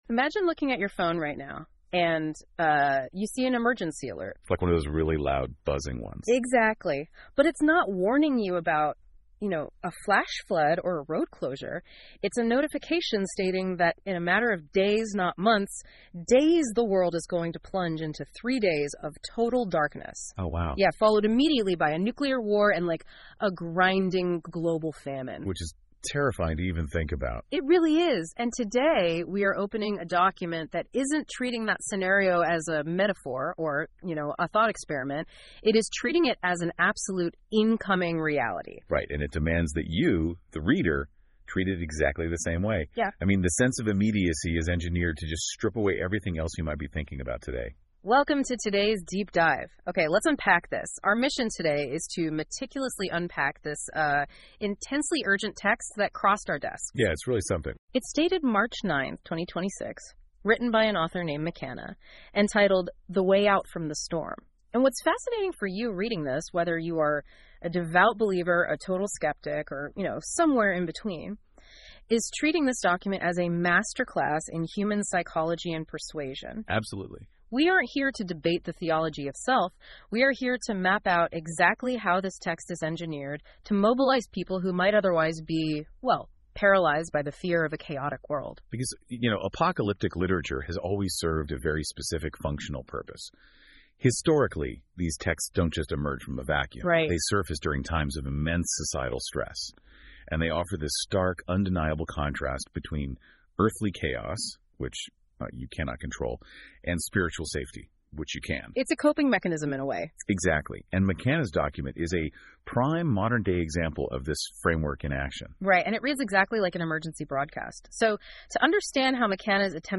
Podcast style breakdown of the PDF